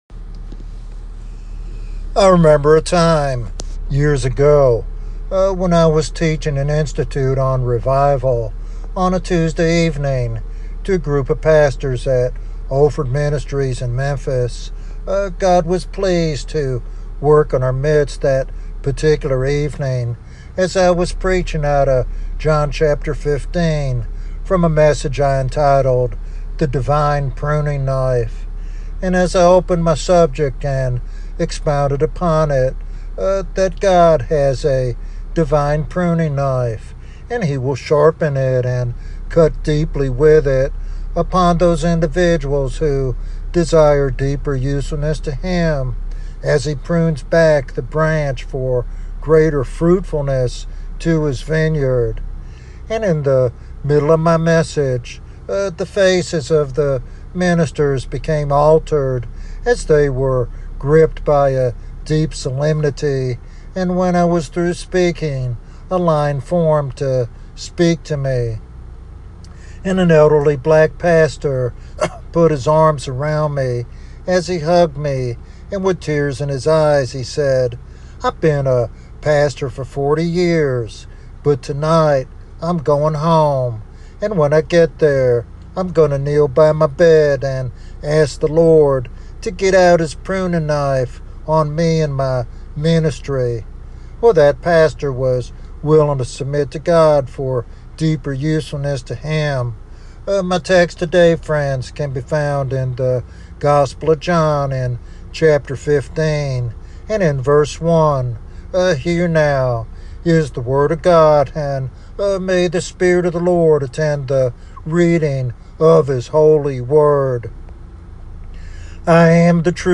In this devotional sermon